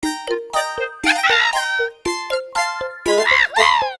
slightly sped-up version